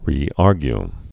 (rē-ärgy)